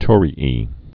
(tôrē-ē)